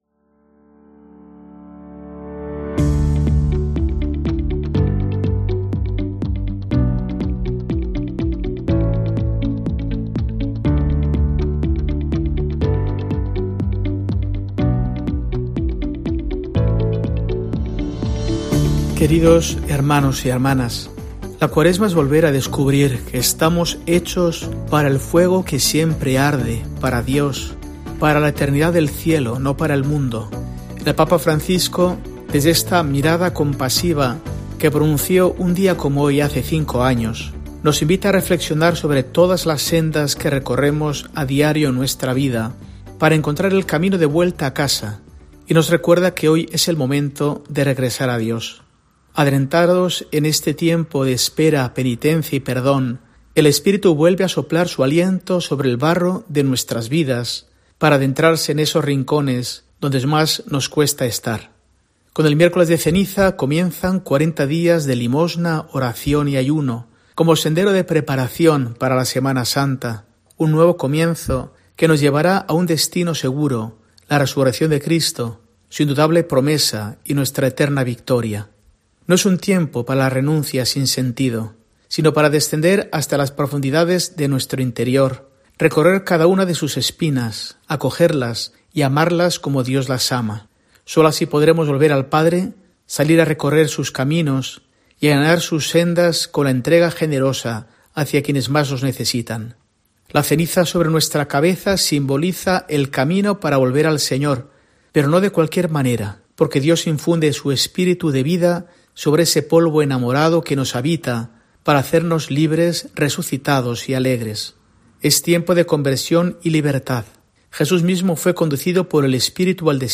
Mensaje del arzobispo de Burgos para el domingo, 18 de febrero de 2024